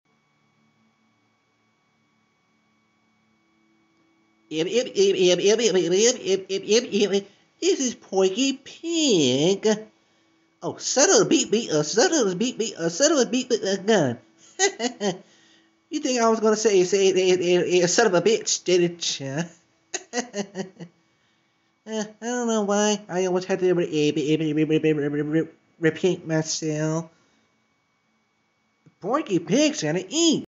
Voice impression of Porky Pig sound effects free download